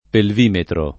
[ pelv & metro ]